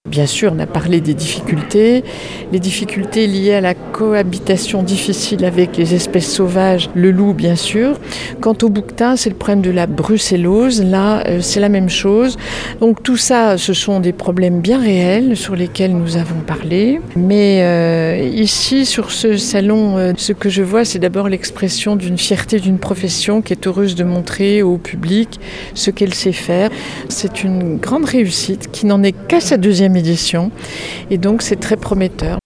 La ministre de l'Agriculture et de la Souveraineté alimentaire, Annie Genevard, était présente ce jeudi. Elle a pu s'entretenir avec les éleveurs.